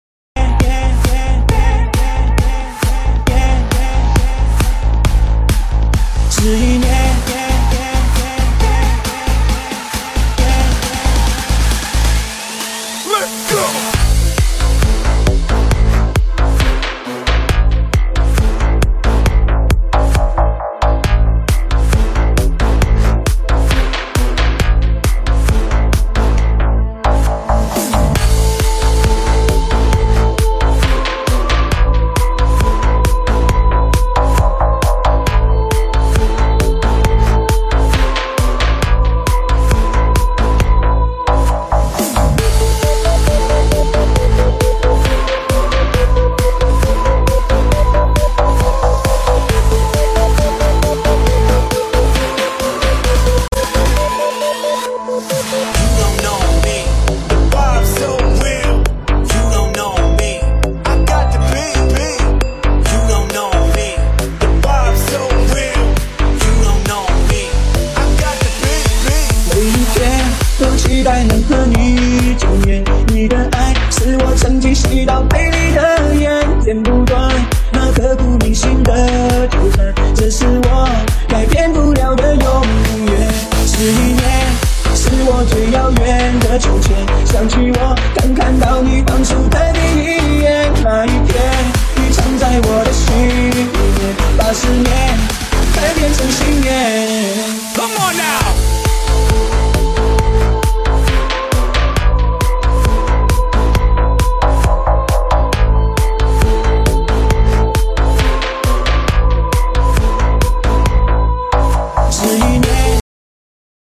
越南VinaHouse